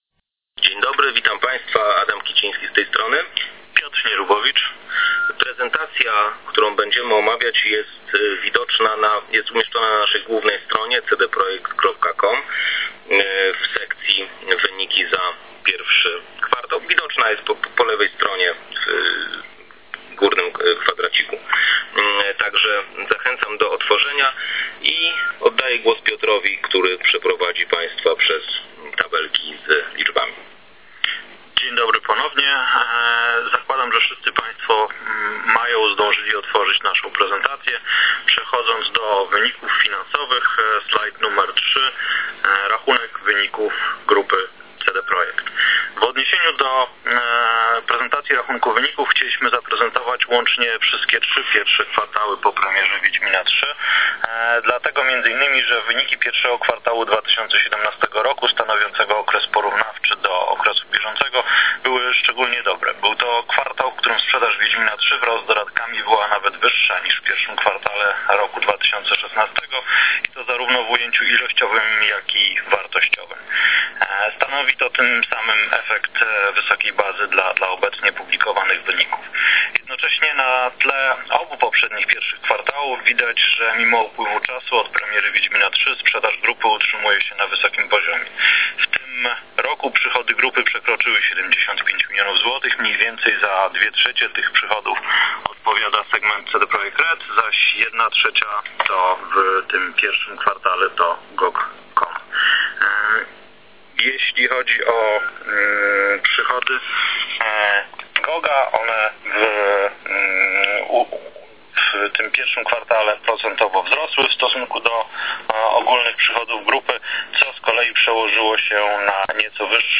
wyniki-cd-projekt-za-1-kw-2018-r-telekonferencja.mp3